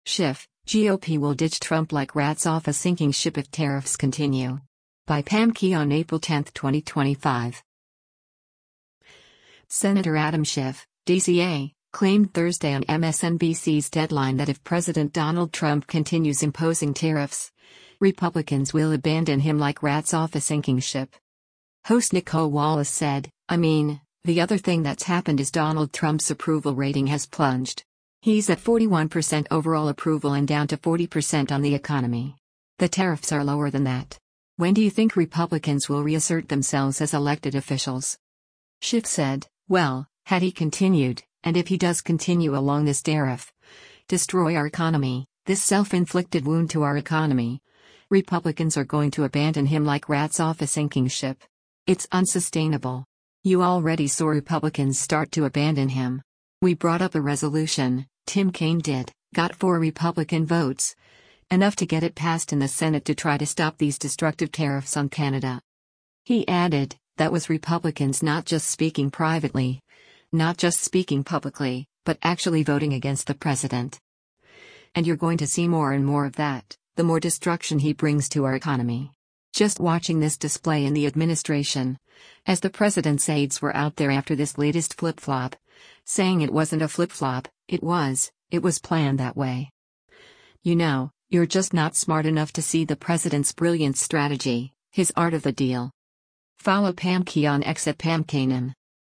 Senator Adam Schiff (D-CA) claimed Thursday on MSNBC’s “Deadline” that if President Donald Trump continues imposing tariffs, Republicans will “abandon him like rats off a sinking ship.”